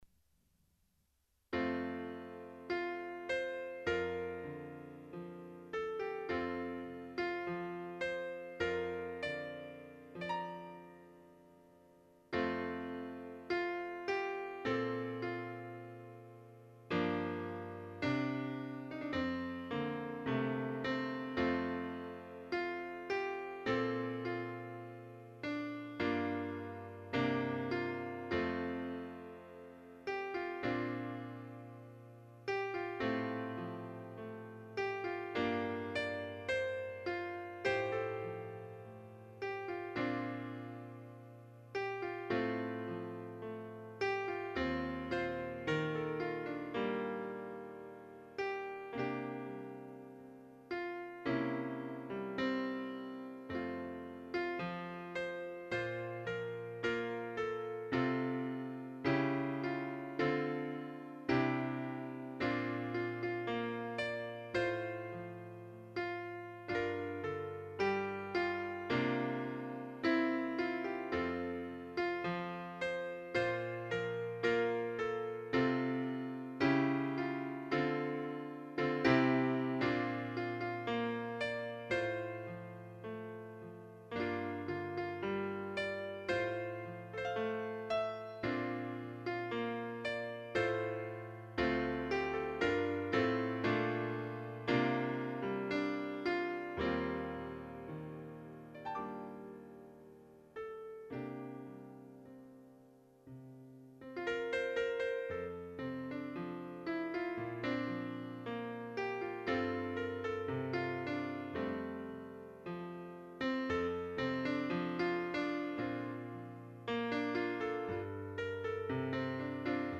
두가지 형태로 샘플링하였고, 말이 샘플링이지, 아주 초보적인 형태의 녹음입니다.
감미로운 멜로디가 제 마음을 휘감네요ㅜㅜ
피아노 음원은 커즈와일의 피아노 음원을 쓰신거 같네요 ^^